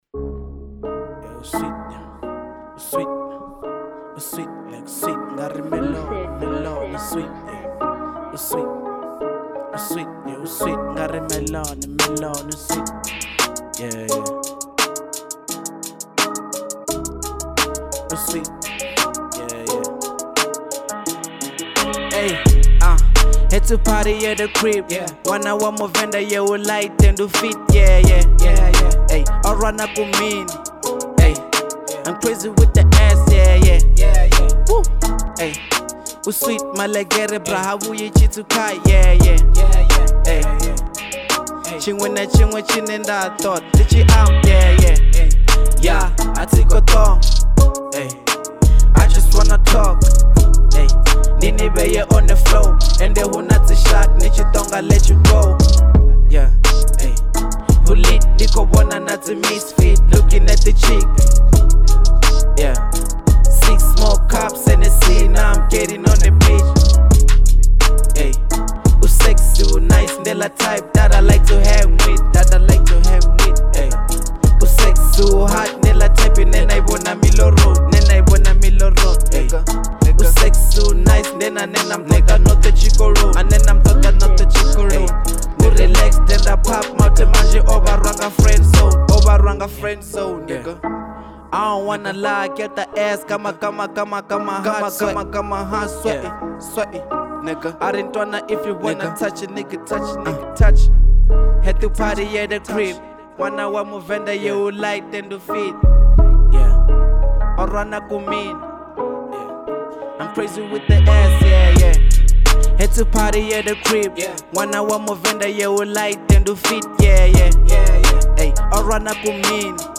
02:17 Genre : Venrap Size